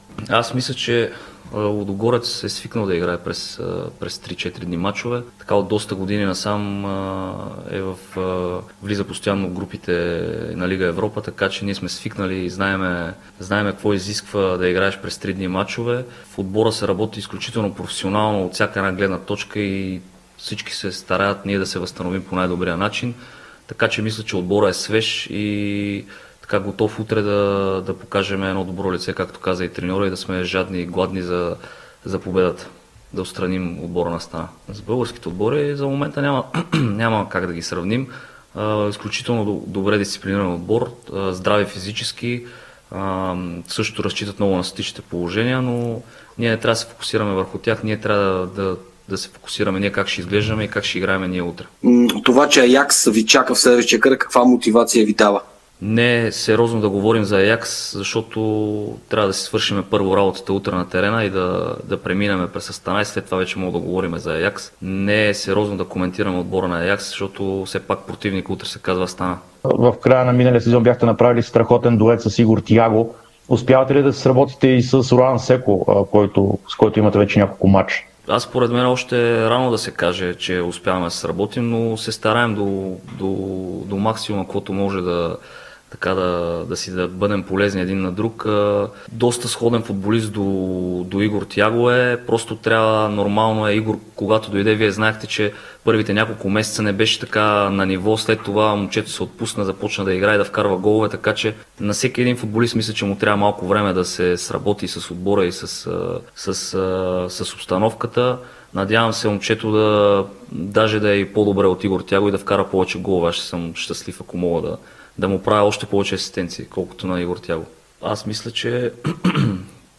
Капитанът на Лудогорец Кирил Десподов говори пред медиите преди срещата реванш от третия предварителен кръг на Лига Европа срещу Астана.